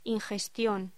Locución: Ingestión
voz